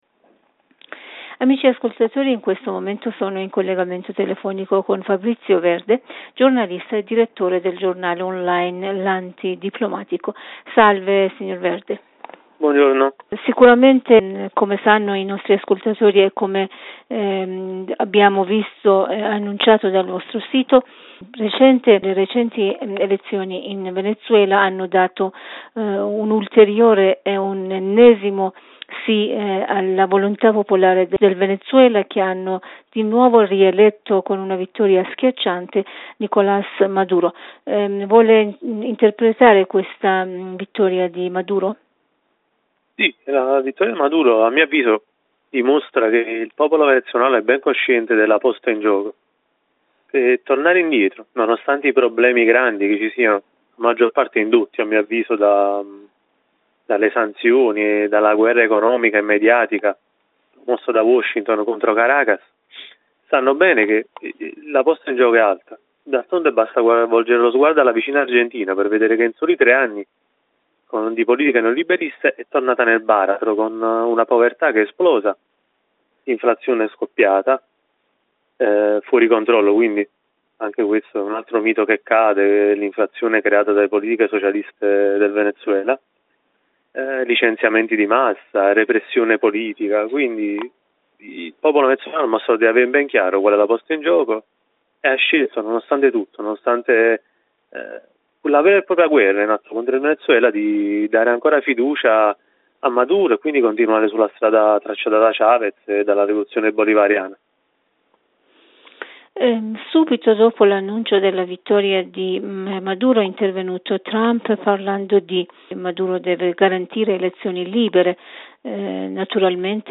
Notiziario / mondo